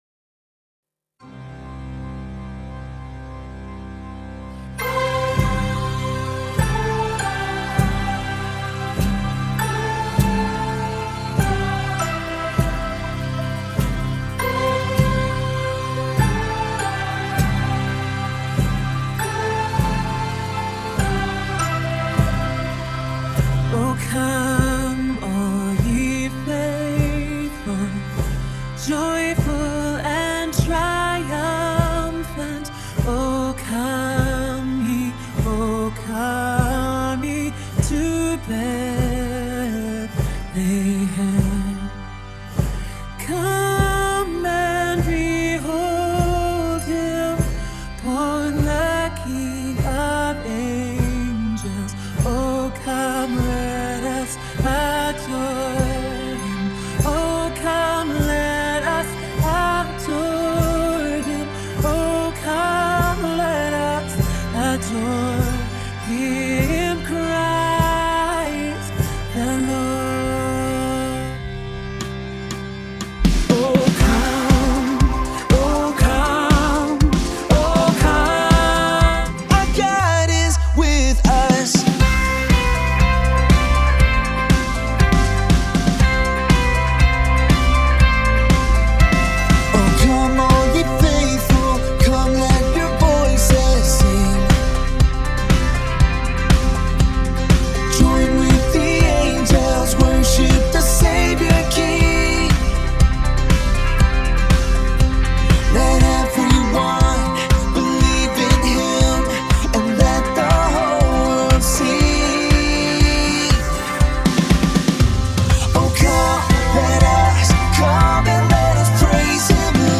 CCHV Christmas Choir Kids 2025
Come-Let-Us-Praise-Him-Kids-Choir.mp3